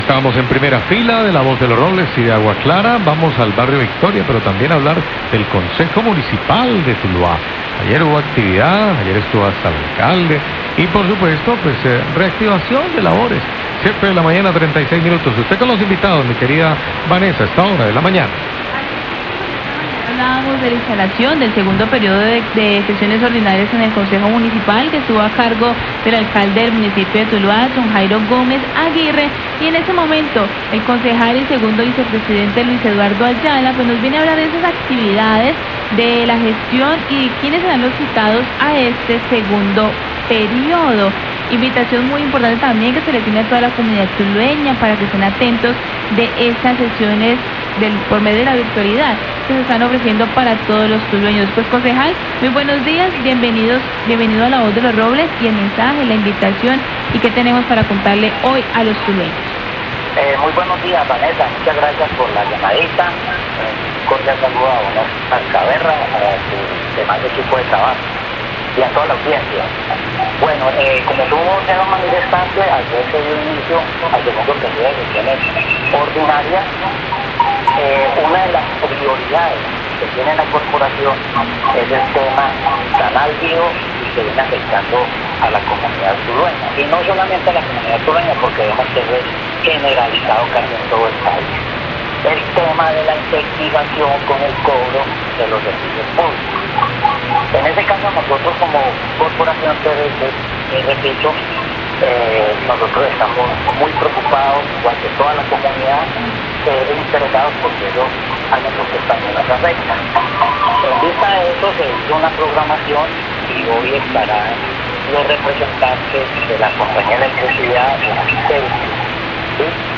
Radio
Concejal Luis Ayala habla de la citación que la corporación edilicia le hizo a la celsia en la jornada de sesiones ordinarias para que explique a la comunidad las razones por las cuáles se presentan tantas quejas por el incremento en el costo de los recibos y su relación el cobro por promedio.